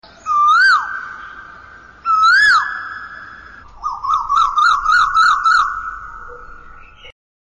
koyal-bird-voice.mp3